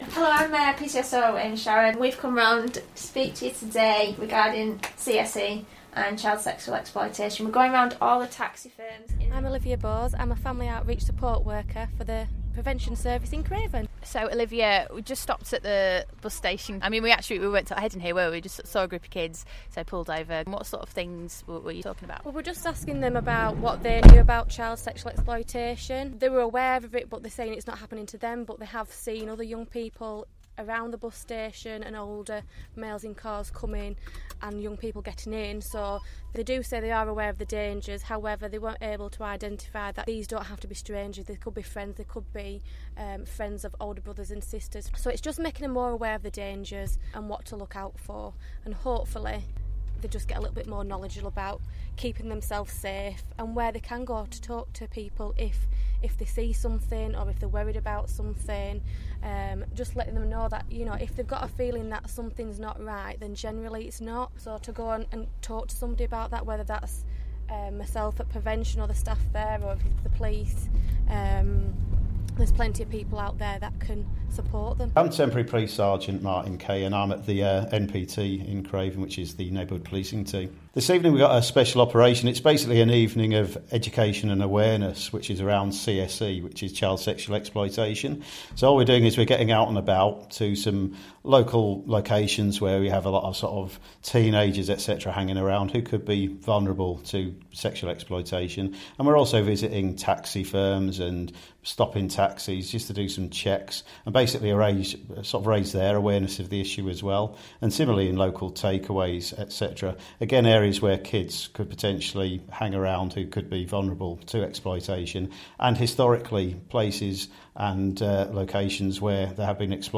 Officers invited Stray FM along to their recent operation to tackle the issue of Child Sexual Exploitation.